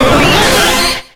Cri de Staross dans Pokémon X et Y.